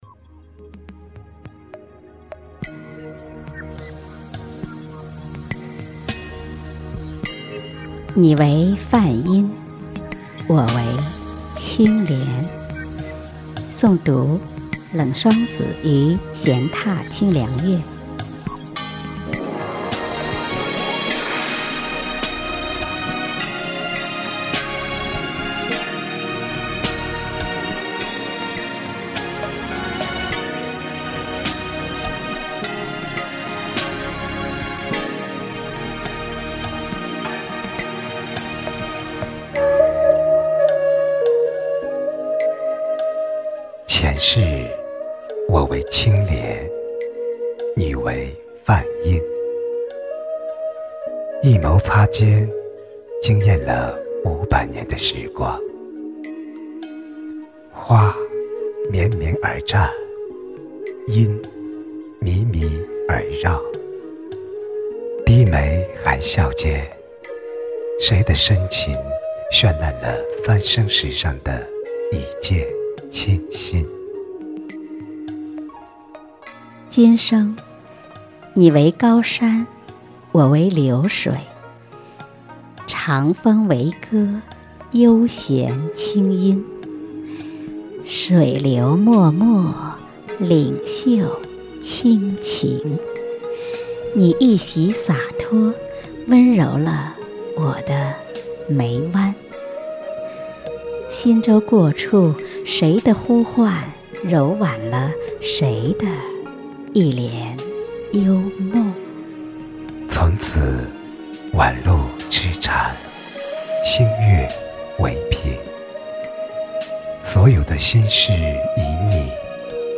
标签: 佛音凡歌佛教音乐